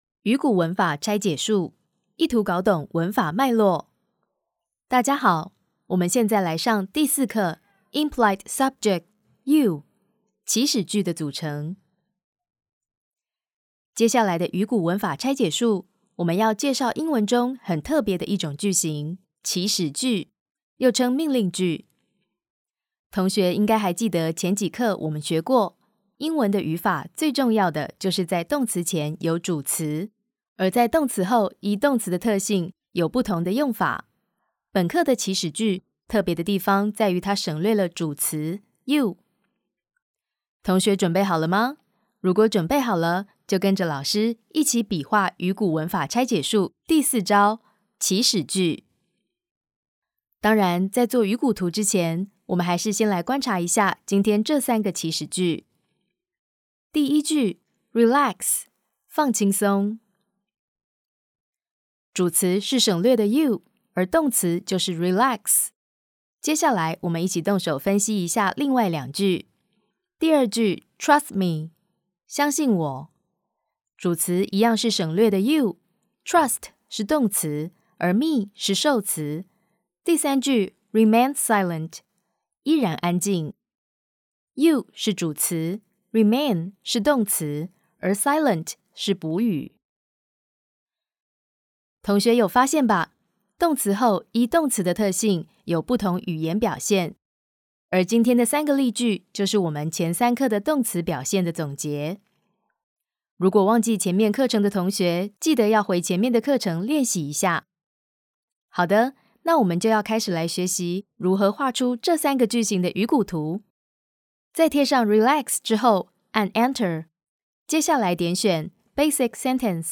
台語配音 國語配音 女性配音員
線上課程 _ 專業親切】魚骨文法拆解術試音
✔ 高辨識度中音女聲，適合企業品牌廣告、銀行、食品、家電類產品旁白